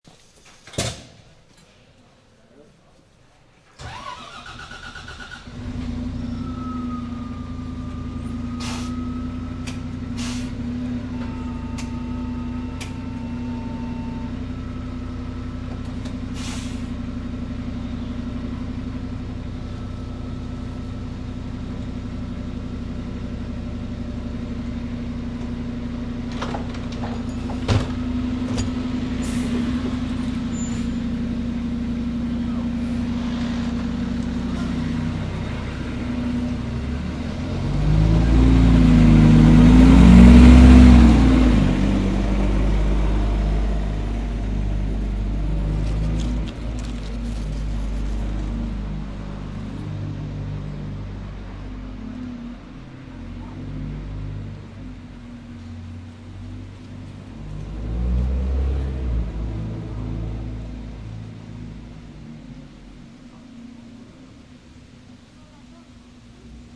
Download Bus sound effect for free.
Bus